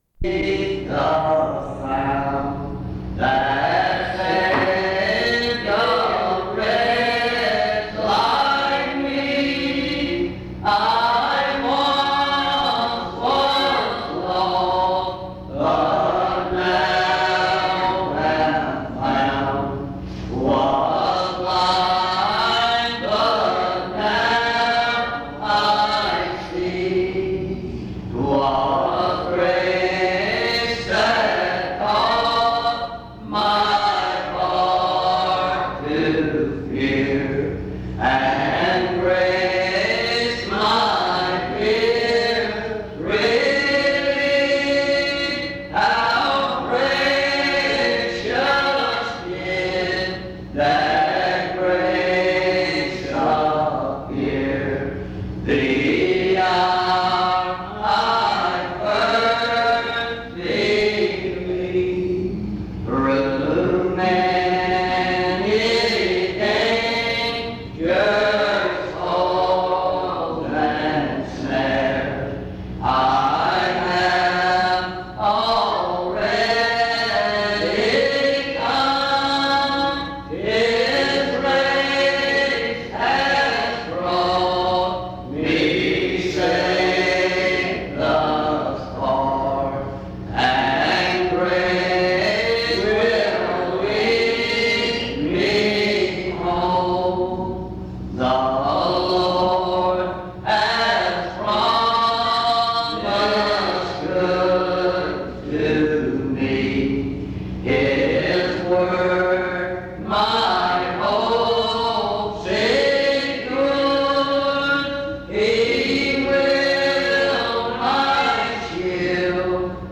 Dans Collection: Reidsville/Lindsey Street Primitive Baptist Church audio recordings La vignette Titre Date de téléchargement Visibilité actes PBHLA-ACC.001_074-B-01.wav 2026-02-12 Télécharger PBHLA-ACC.001_074-A-01.wav 2026-02-12 Télécharger